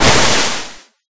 Water1.ogg